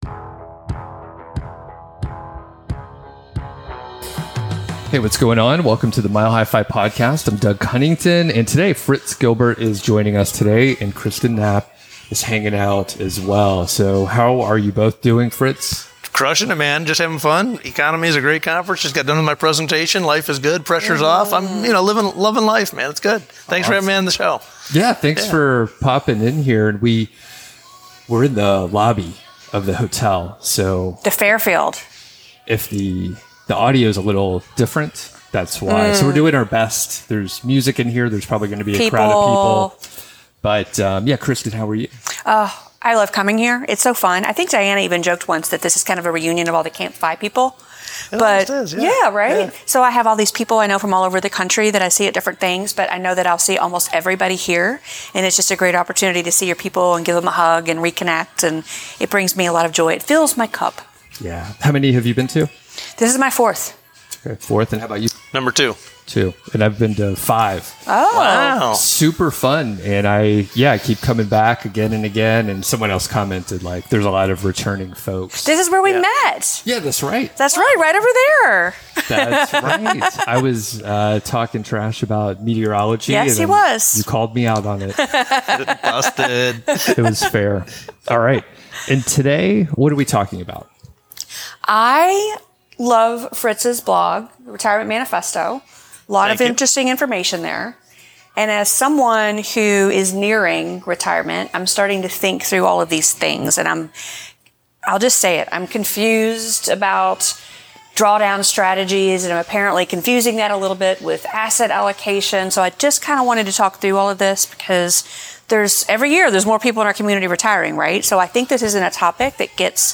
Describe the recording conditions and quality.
at the EconoMe Conference about various drawdown strategies and spending in early retirement.